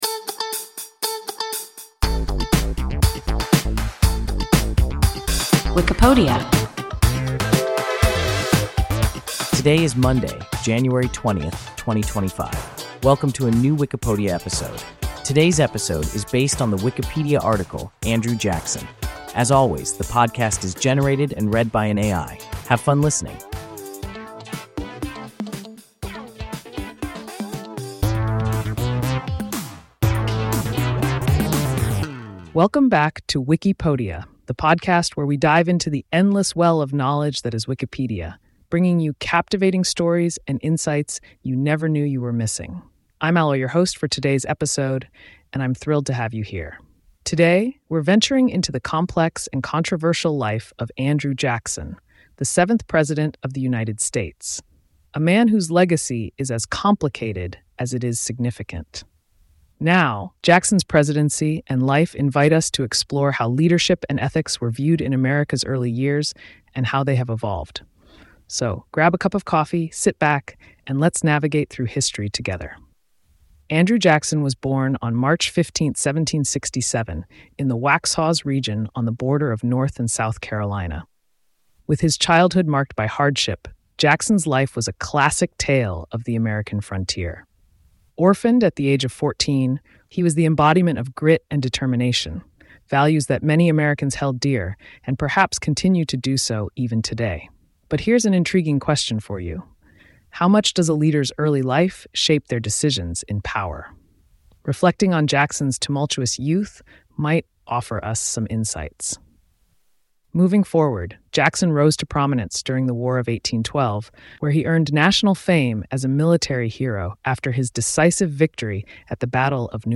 Andrew Jackson – WIKIPODIA – ein KI Podcast